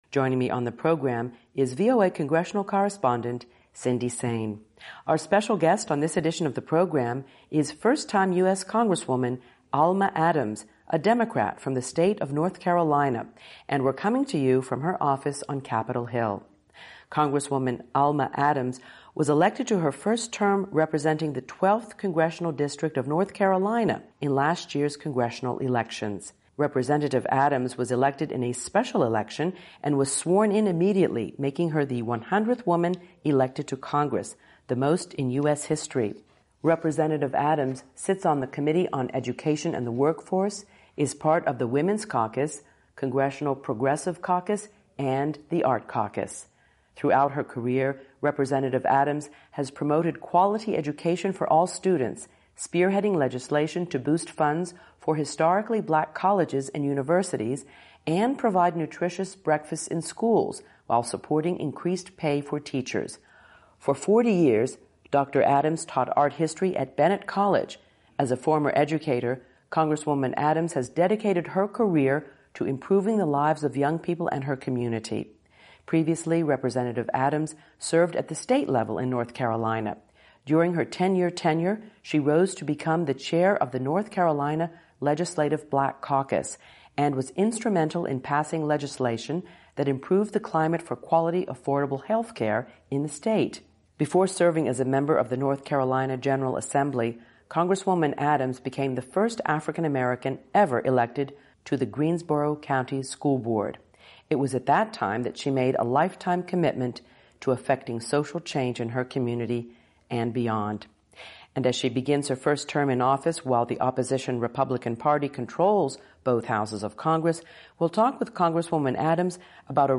Prominent Washington correspondents discuss topics making headlines around the world .